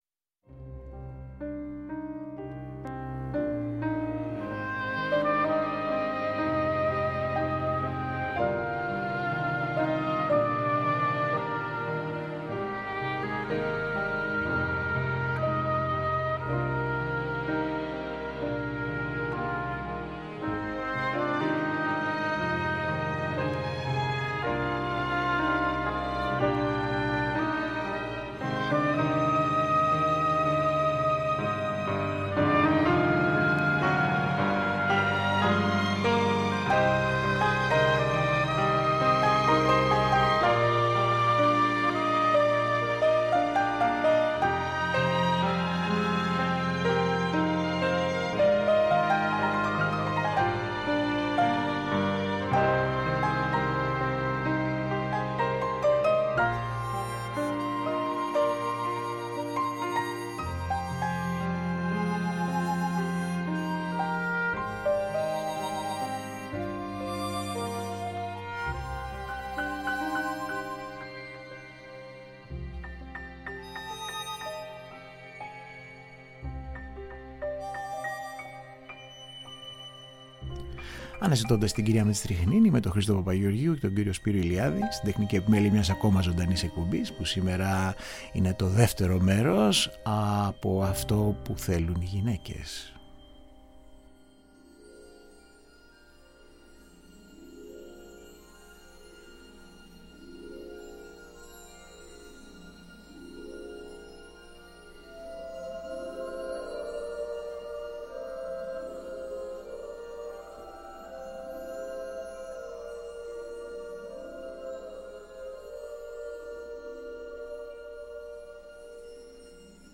Original Soundtrack